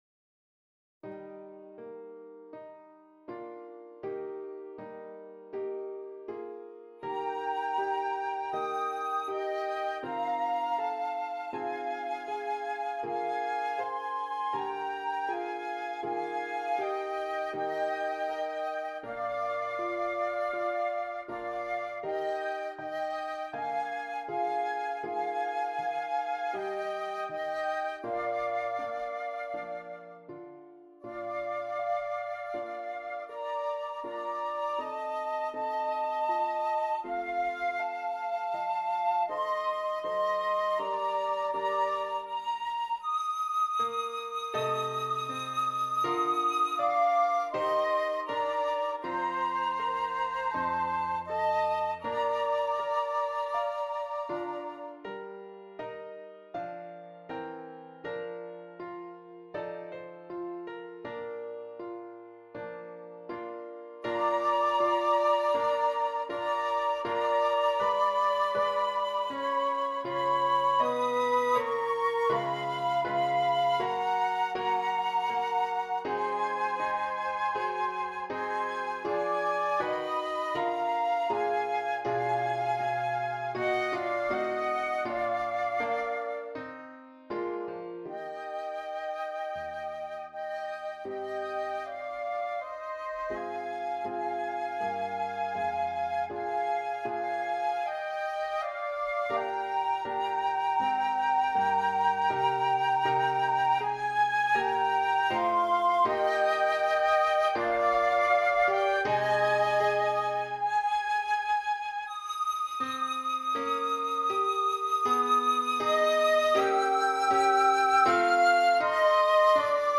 2 Flutes and Keyboard